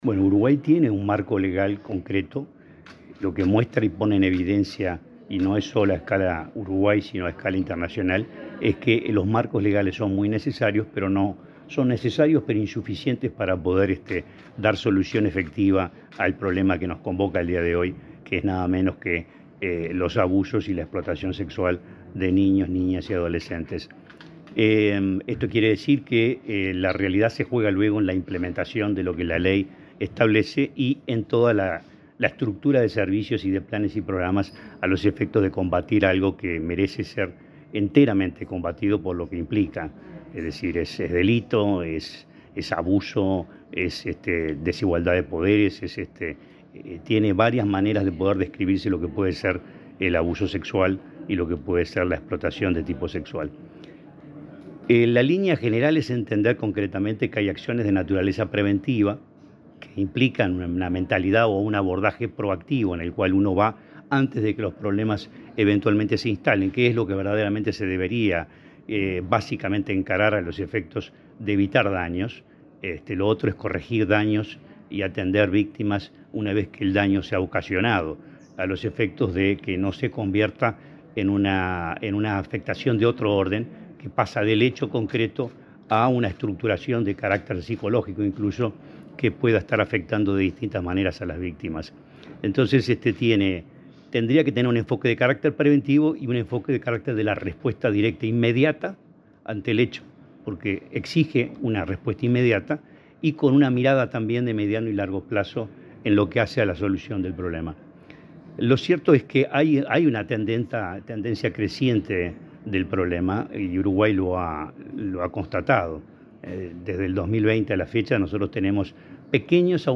Declaraciones del presidente del INAU, Guillermo Fossati
El presidente del Instituto del Niño y Adolescente del Uruguay (INAU), Guillermo Fossati, dialogó con la prensa, antes de participar, este jueves 7 en